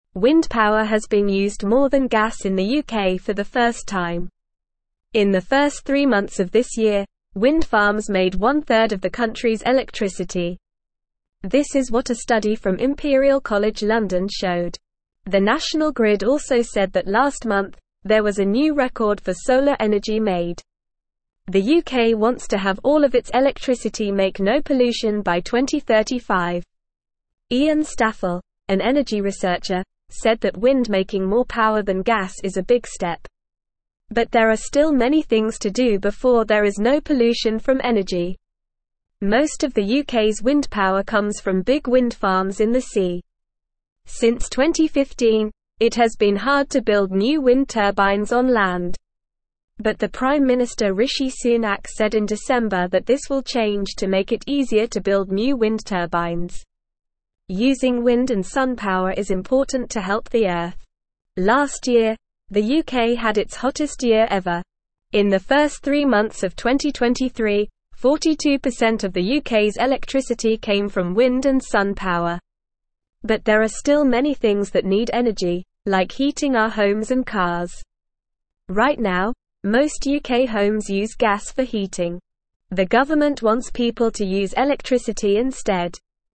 Normal
English-Newsroom-Beginner-NORMAL-Reading-UK-Wind-Power-Beats-Gas-First-Time.mp3